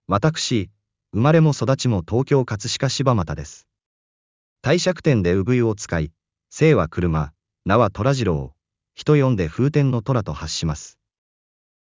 音声読み上げソフト「音読さん」の発声で、聞いみる → クリック